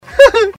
Laugh 18